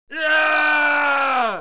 دانلود آهنگ نبرد 5 از افکت صوتی انسان و موجودات زنده
جلوه های صوتی